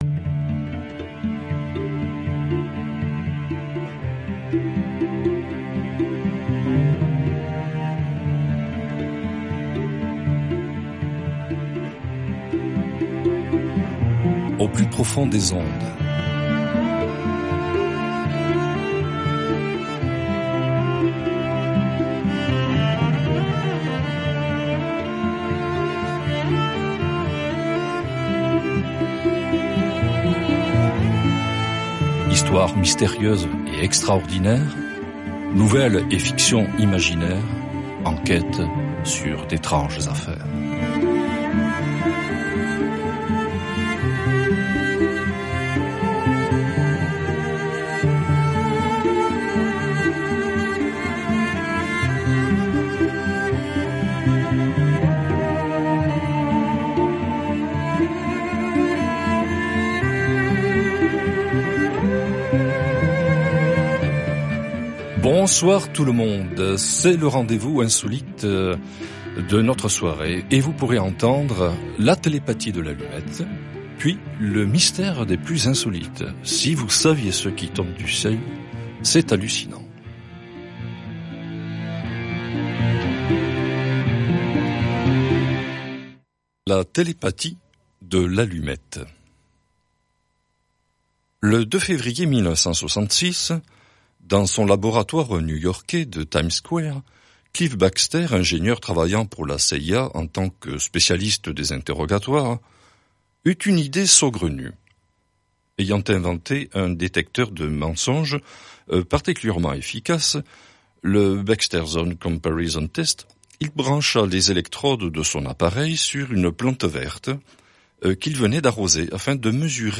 Une sélection jazz pour ouvrir les oreilles et les cœurs de tous les publics.